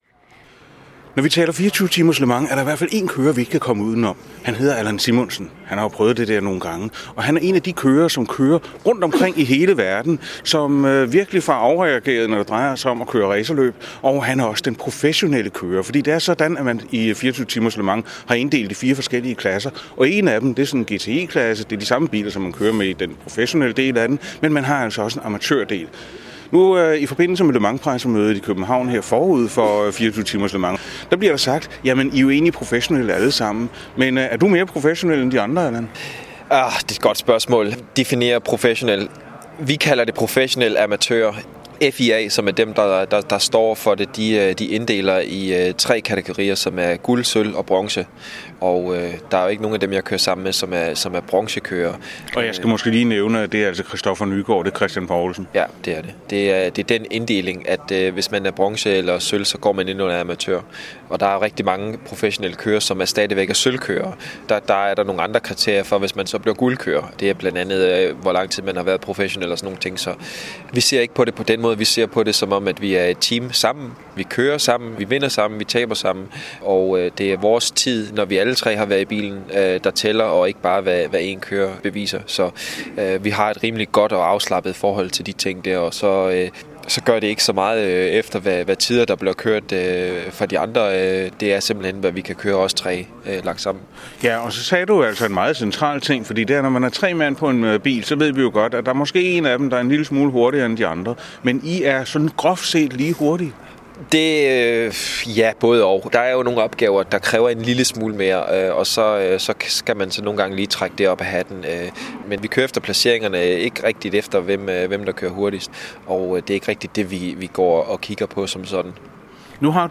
Interview i Motorradioen med Allan Simonsen op til Le Mans 2013